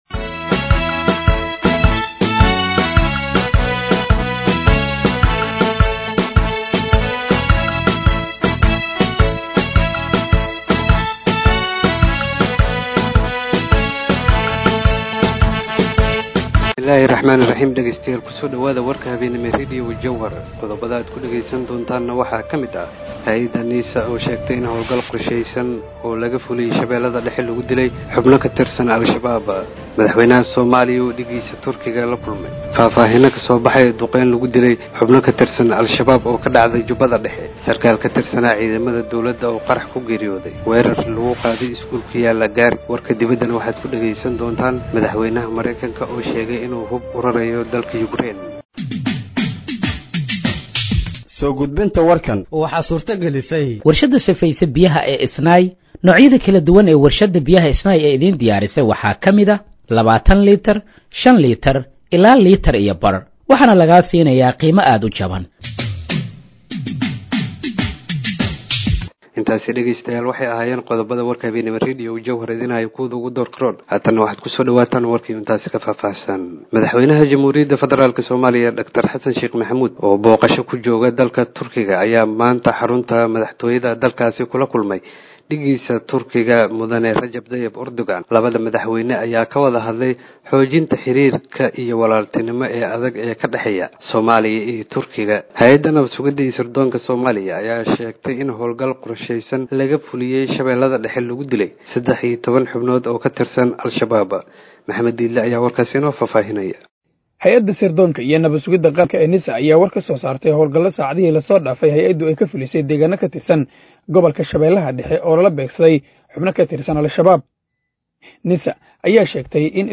Dhageeyso Warka Habeenimo ee Radiojowhar 11/07/2025
Halkaan Hoose ka Dhageeyso Warka Habeenimo ee Radiojowhar